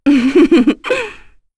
Epis-Vox_Happy1.wav